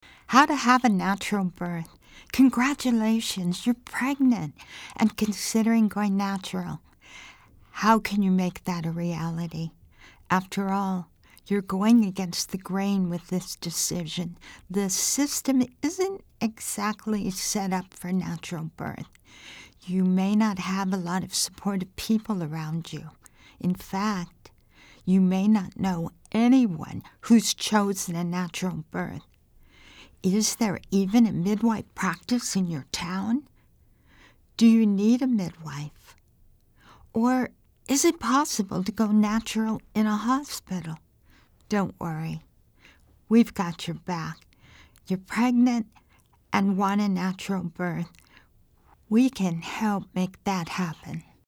Voiceover
Natural-childbirth-commercial.mp3